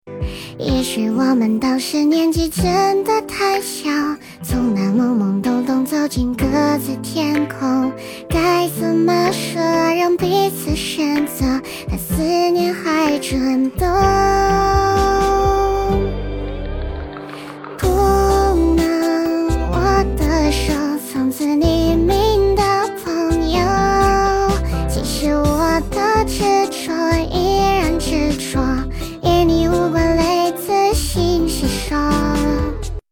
早芝 少萝音 能唱歌的RVC模型
唱歌推理